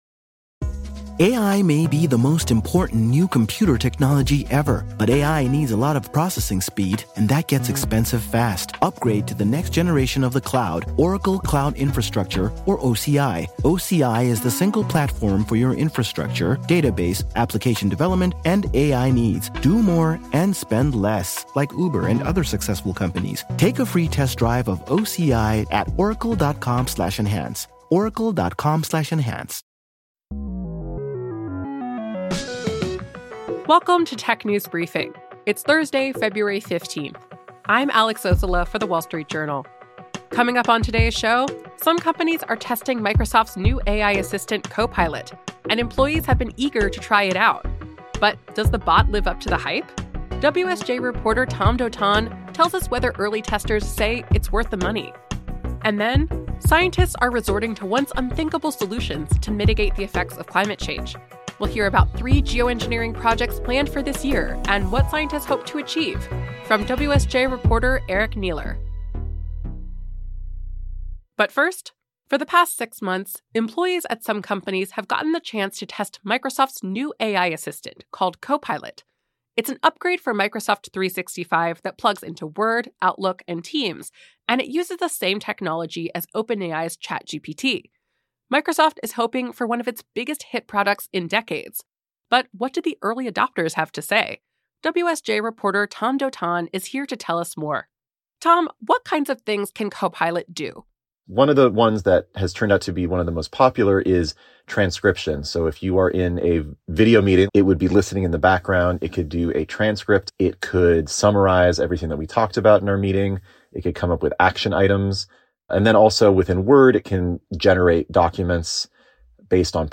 WSJ reporter